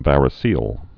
(vărĭ-sēəl)